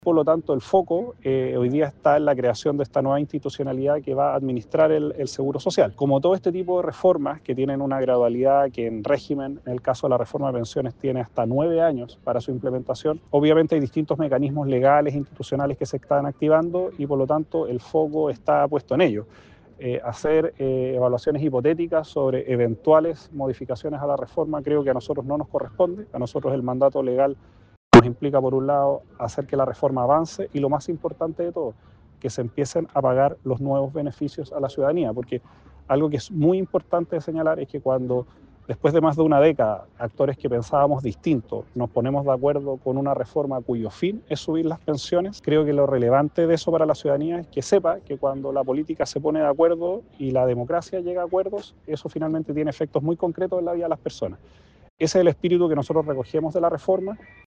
En Concepción: Ministro Boccardo participó en conversatorio sobre el inicio del pago de beneficios de la Reforma de Pensiones - Radio UdeC